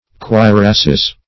Cuirass \Cui*rass"\ (kw[-e]*r[.a]s", or kw[=e]"r[a^]s; 277), n.;
pl. Cuirasses(-[e^]z). [F.cuirasse, orig., a breastplate of